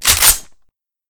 p228_slidepull_sil.ogg